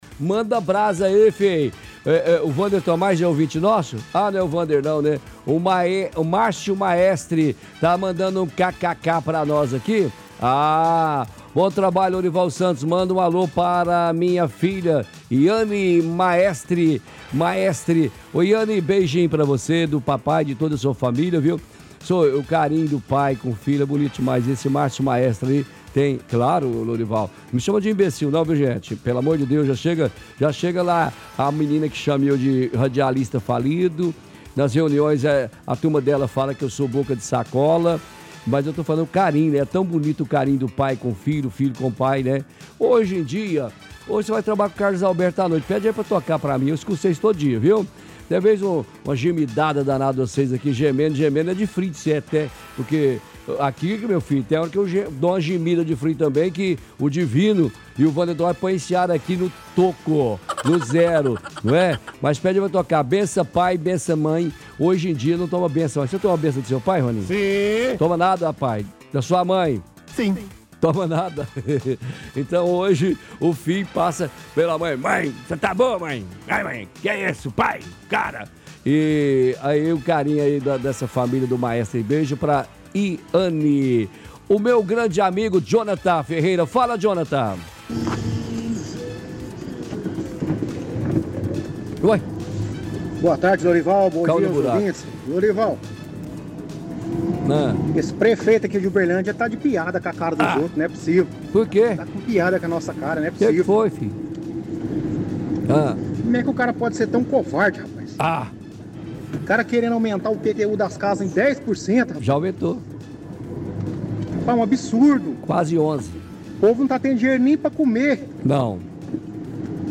Ligação Ouvintes – IPTU/Investigação Fantástico
Ligação-Ouvinte-Prefeito-Bois.mp3